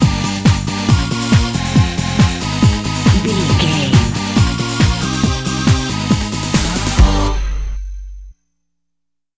Uplifting
Aeolian/Minor
Fast
drum machine
synthesiser
electric piano
Eurodance